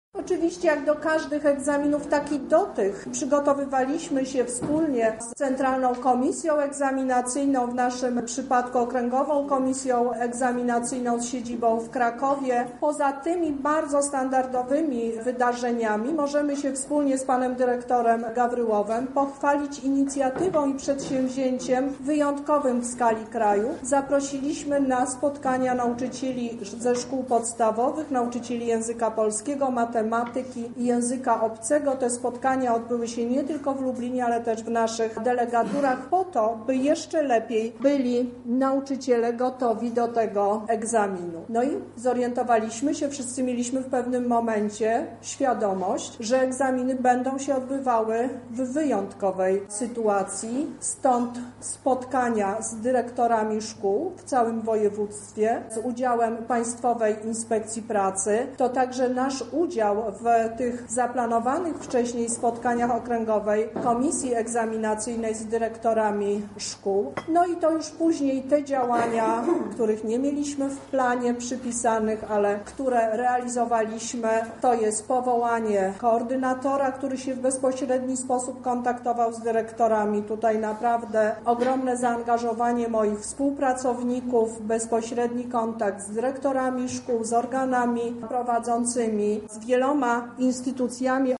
Lubelska Kurator Oświaty Teresa Misiuk tłumaczy jak wyglądały przygotowania do sesji: